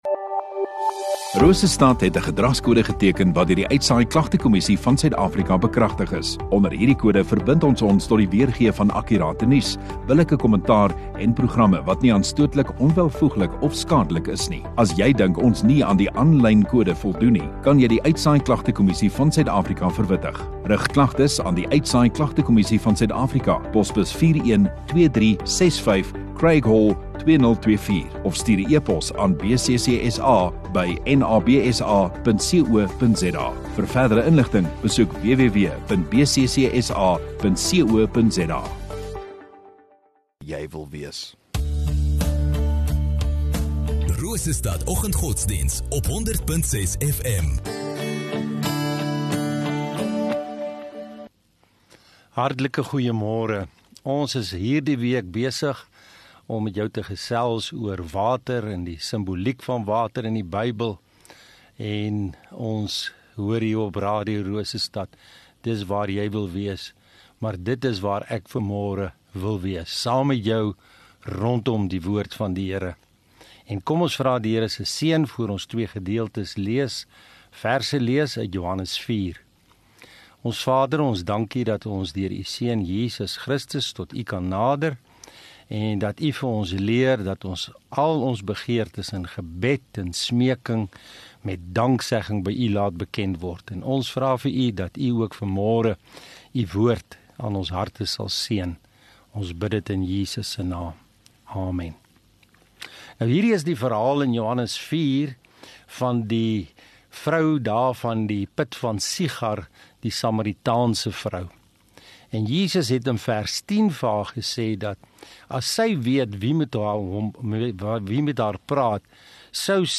9 Apr Donderdag Oggenddiens